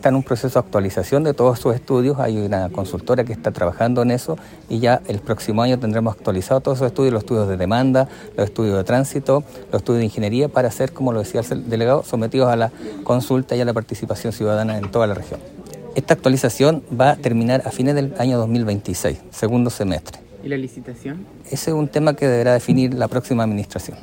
El seremi (s) de Obras Públicas en La Araucanía, Marcelo Muñoz, explicó que todavía se encuentran en la etapa de actualización de los estudios de la iniciativa.